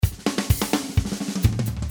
128 BPM Malfuf (41 variations)
This loop track contains 41 loops of pure arabic malfuf.
The track length is 4 minutes and it has a lot of tom fills, 2 loops of baladi style in 128 bpm,